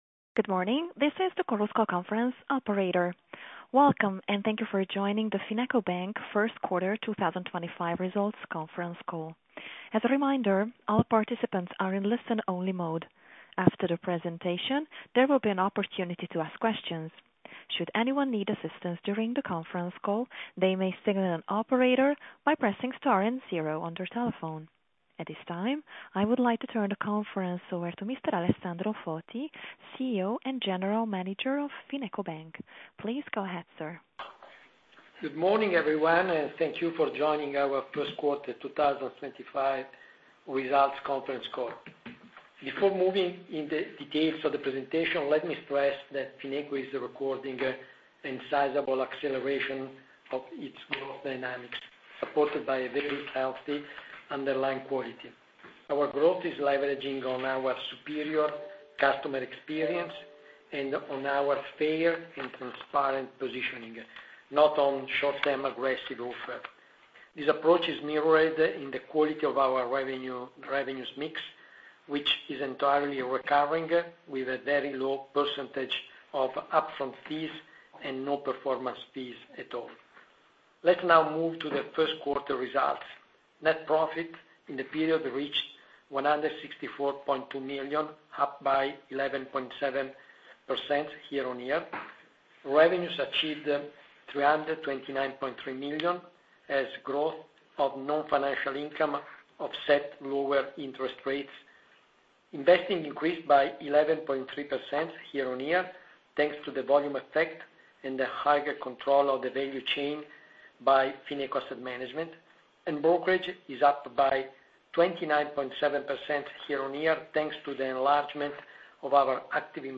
Conference call
conference-call-1Q25.mp3